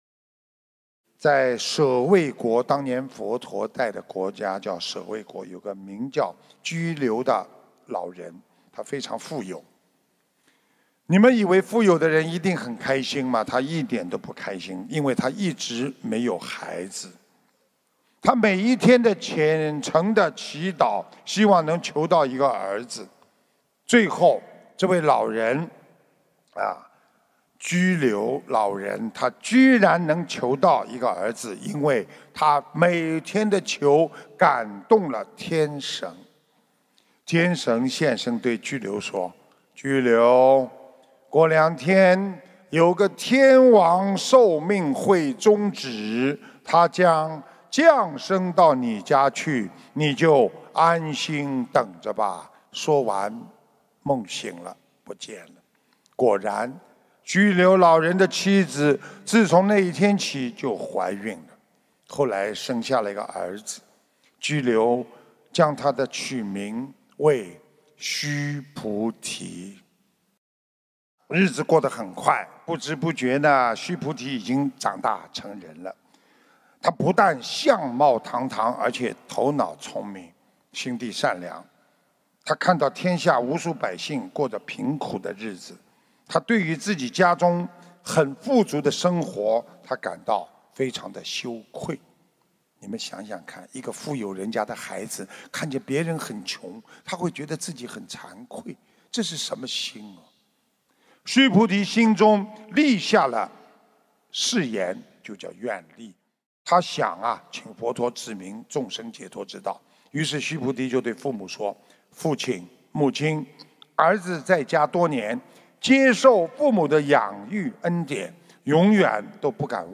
音频：【慧命有根 佛情有源】须菩提的故事 ｜ 新加坡辅导讲座 2018年5月20日